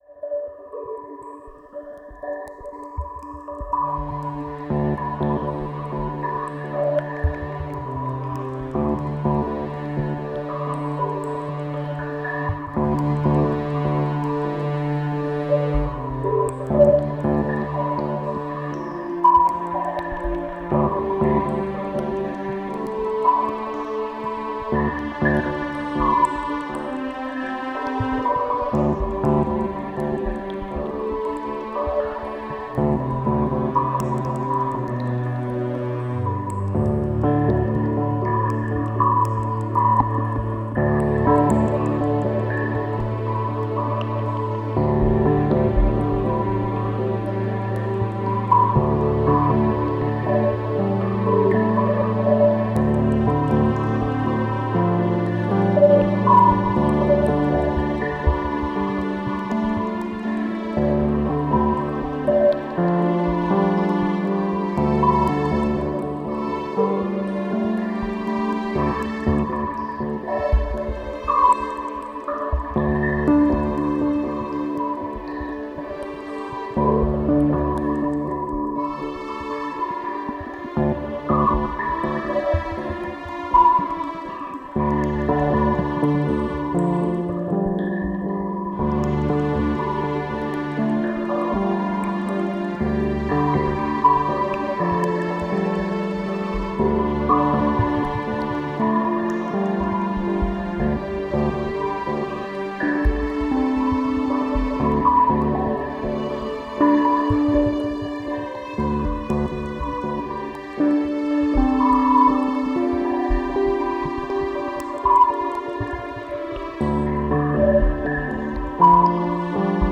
Gechilltes Piano mit Delay. Friedvolle Atmospähre.